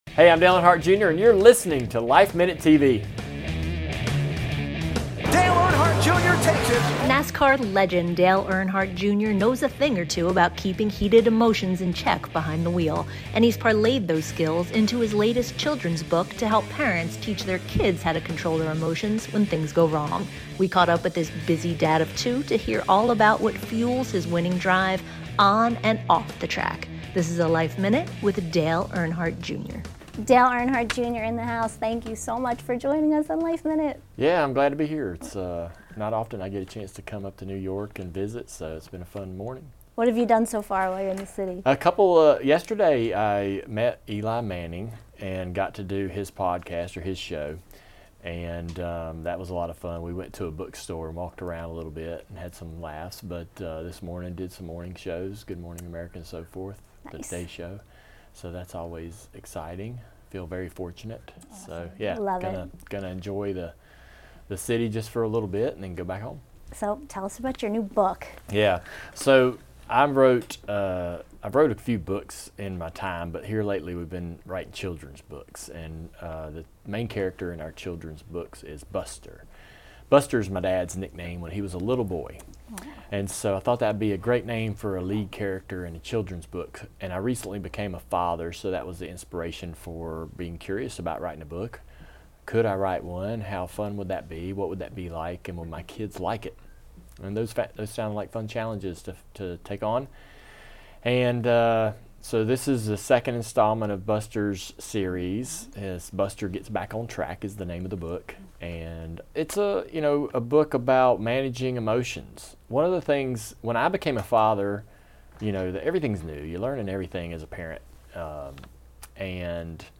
We recently caught up with this mega powerhouse and devoted husband and dad of two at the LifeMinute Studios to hear all about what fuels his winning drive on and off the track. This is a LifeMinute with Dale Earnhardt Jr.